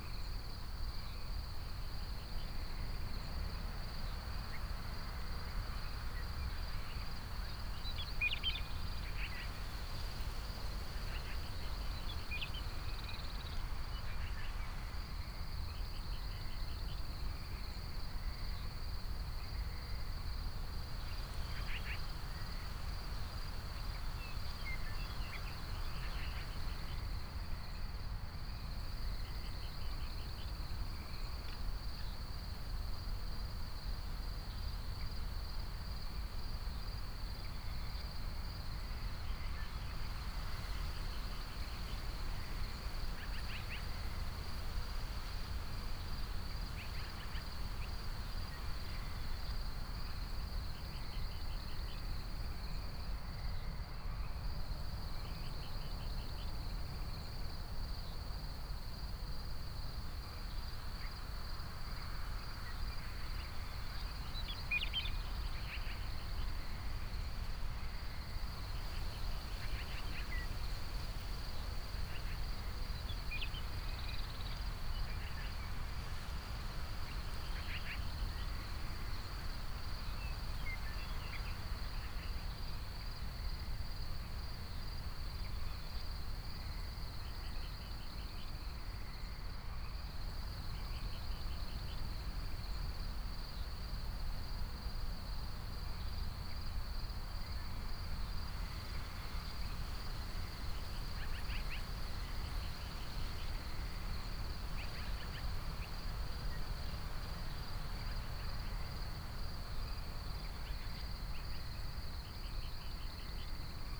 AmbienceForest.wav